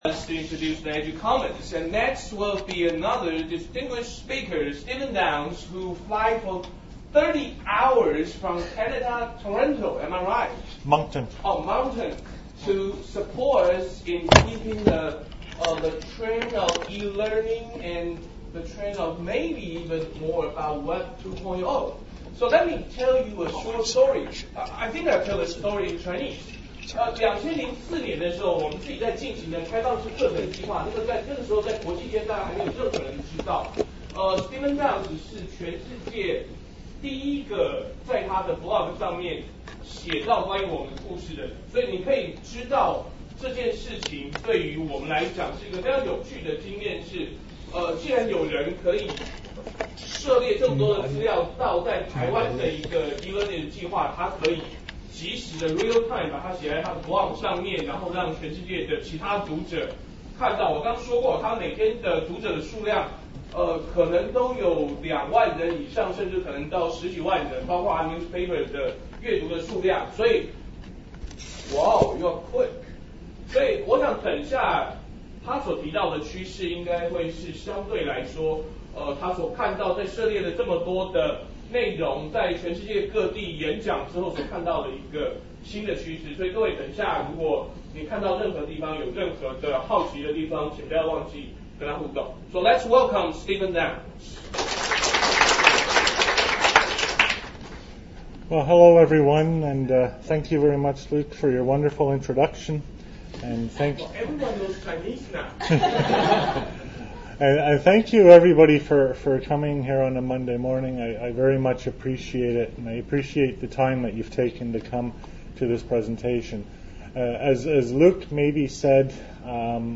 This presentation argues for a model of OERs based on community use and production.